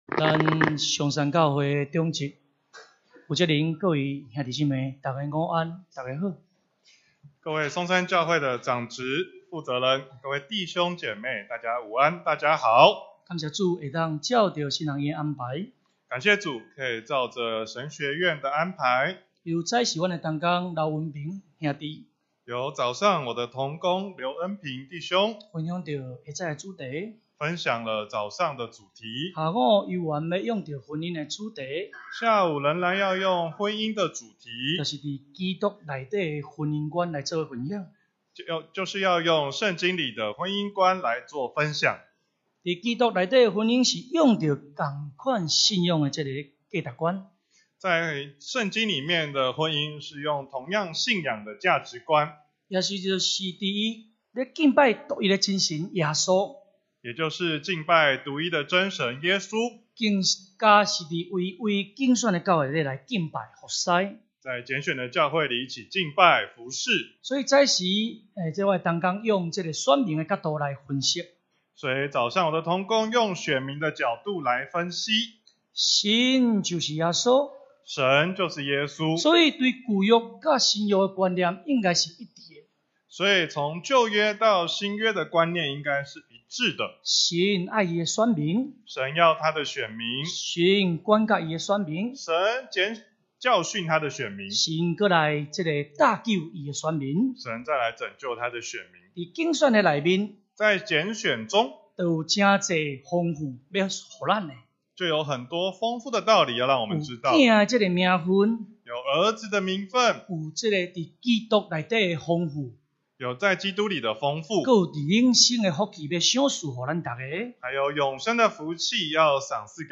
2018年6月份講道錄音已全部上線